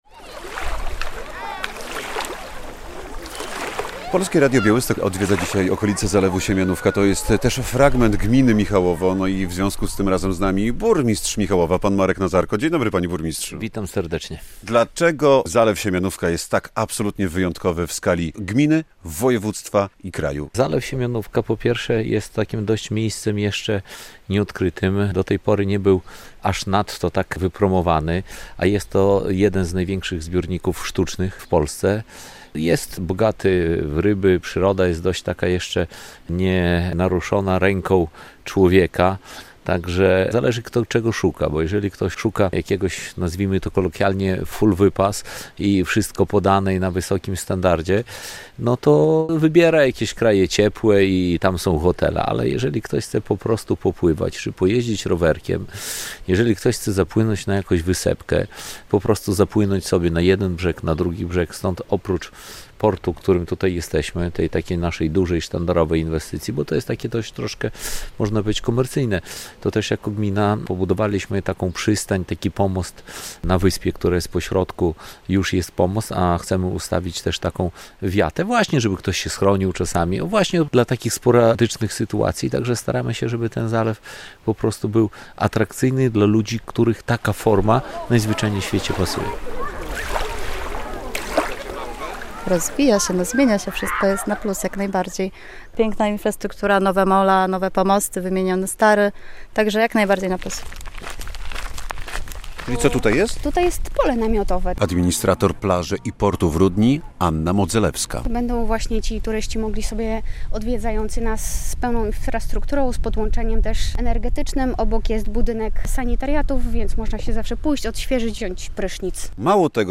Sporty wodne na Zalewie Siemianówka - relacja